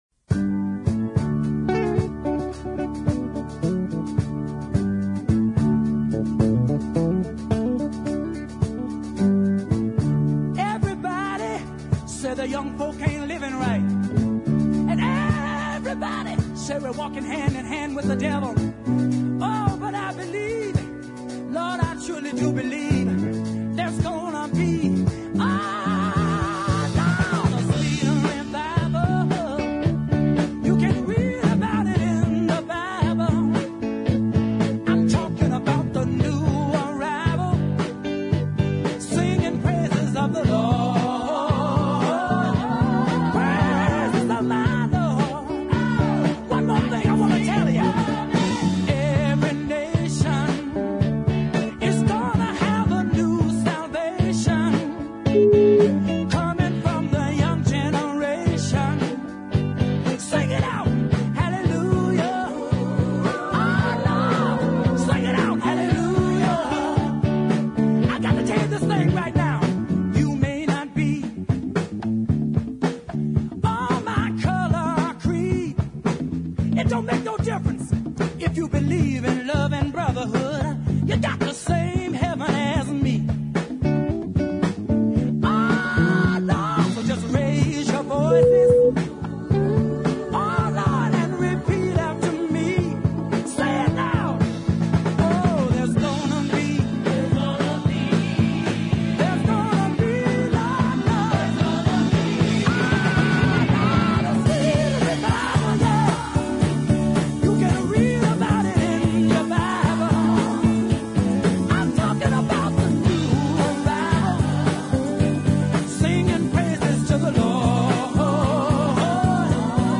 He continued this gospel feeling with his next 45